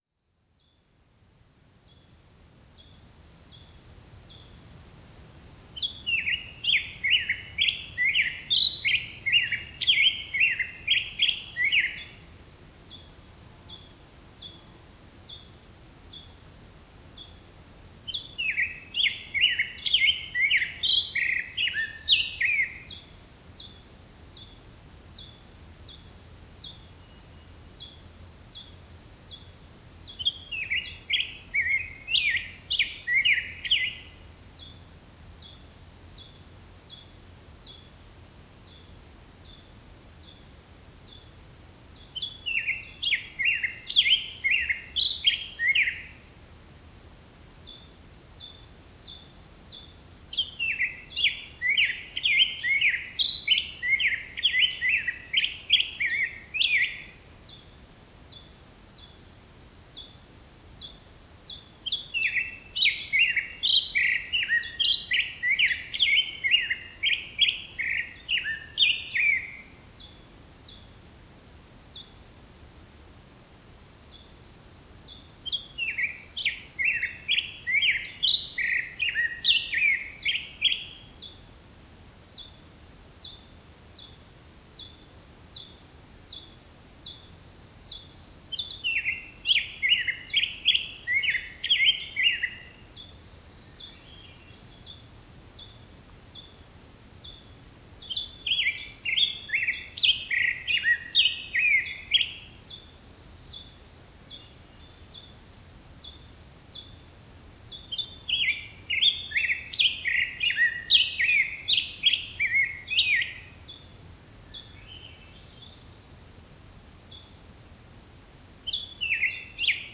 Recordings from Juan de Fuca Provincial Park
62. Black-headed Grosbeak sings lead with backup from a Pacific-slope Flycatcher and other songbirds
62_Black-headed_Grosbeak.wav